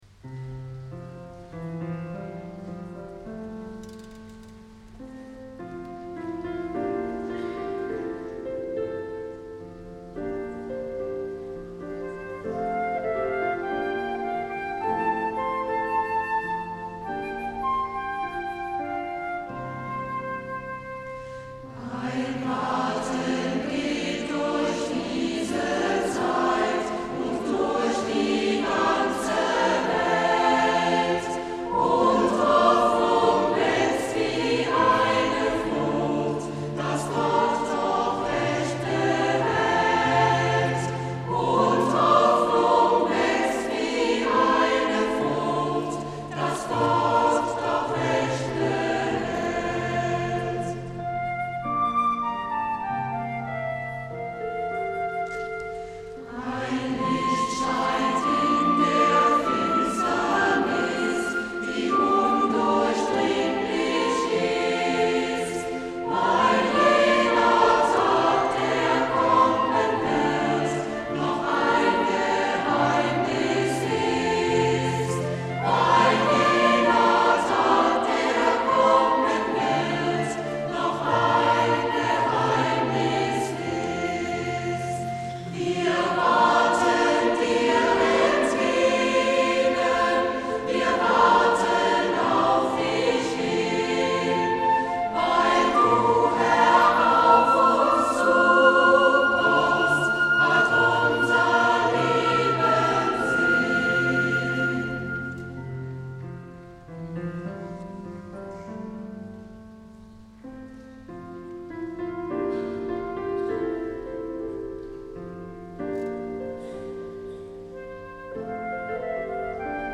Weihnachtskonzert 2012
Hören Sie Auszüge aus dem gut besuchten Weihnachtskonzert 2012 in St. Martin und erhalten Sie einige Eindrücke der Atmosphäre dieser gelungenen Veranstaltungvom 16. Dezember 2012.